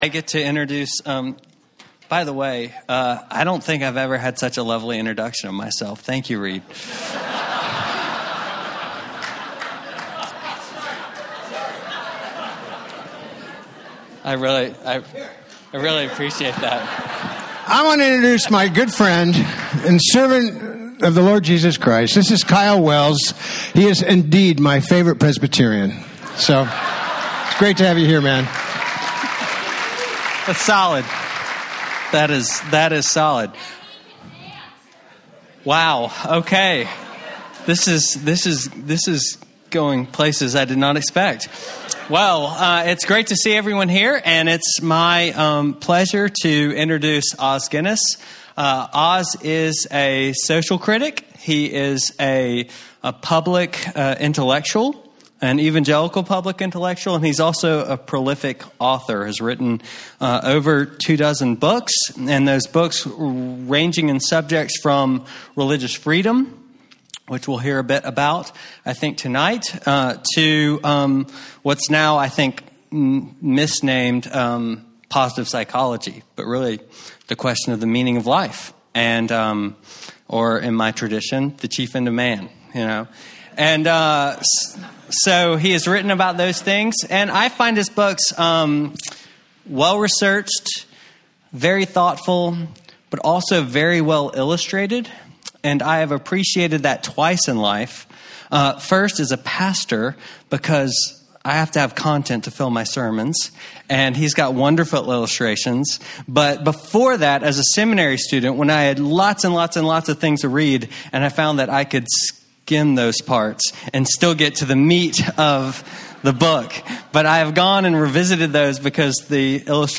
Preacher: Guest Preacher…